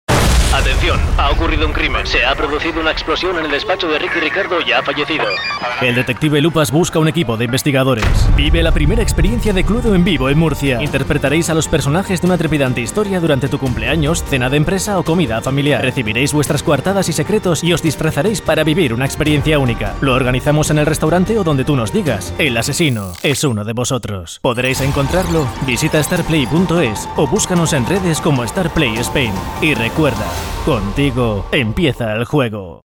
Cuñas de publicidad
Grabamos cuñas de radio con locutores profesionales y las editamos para que tengan un sonido de cine
Cuñas potentes, con una cuidada realización sonora, efectos y masterización potente.